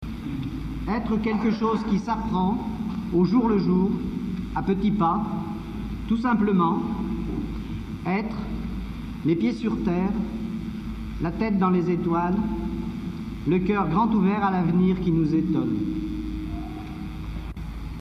"Les Ménétri" le 13 mai 1977 Cathedrale St-Vincent MACON
Extraits audio du Concert du 13 mai 1977
03.Presentation